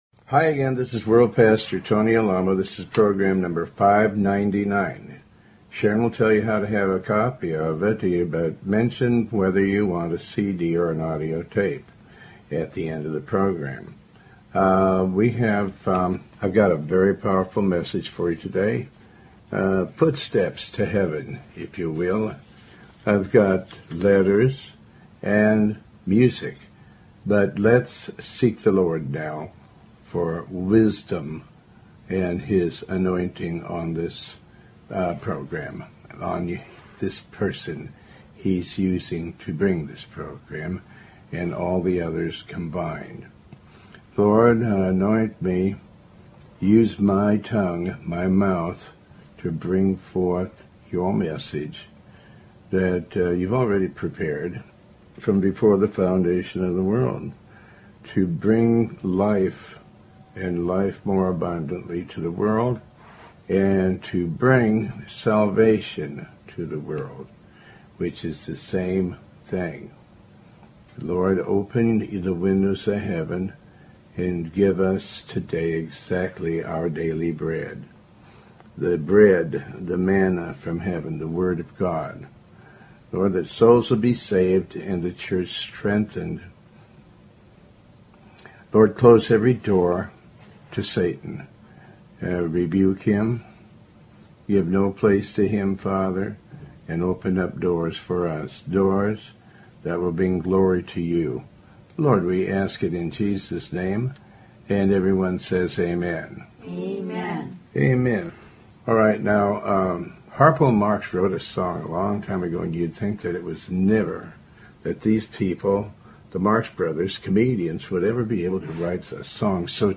Talk Show Episode, Audio Podcast, Tony Alamo and The Lord may be testing you to see if you will stick with Him thru thick and thin.